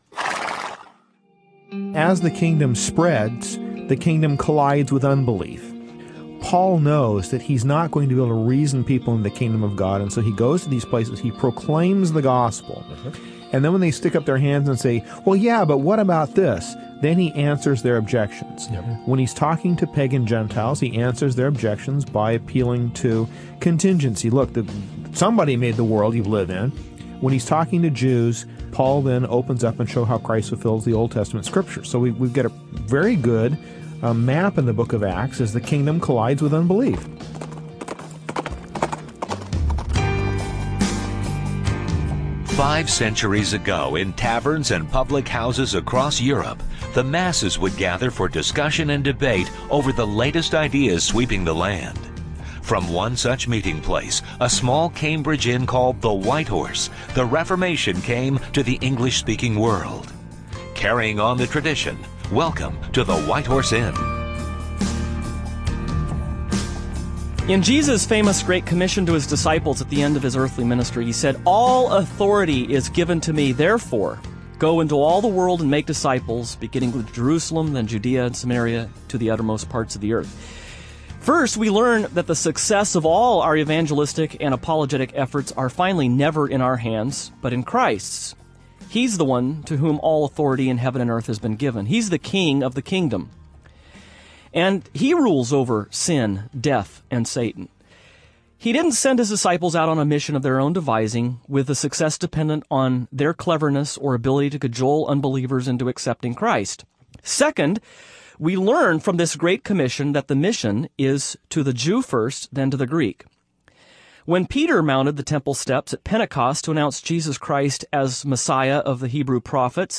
On this edition of the White Horse Inn, the hosts will discuss the character of Christian proclamation, especially in the context of unbelievers.